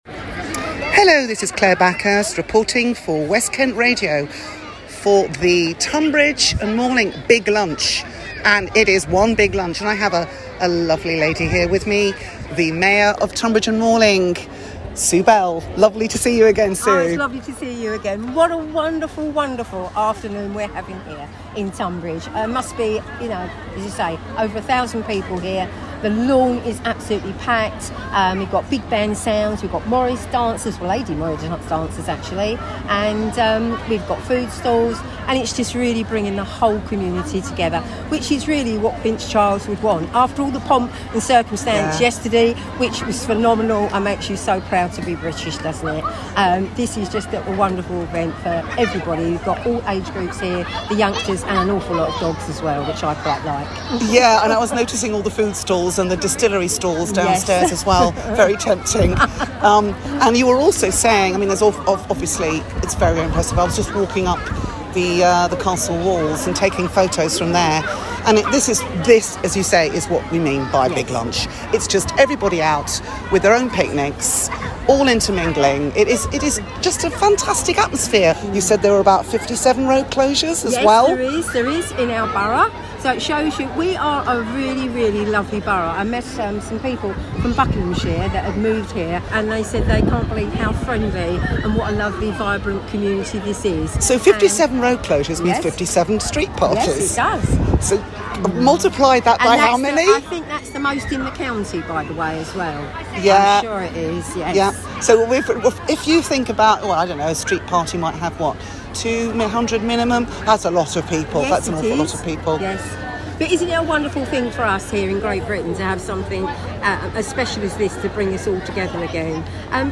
As part of the coronation celebrations Tonbridge & Malling Borough Council put on a Big Lunch at Tonbridge Castle.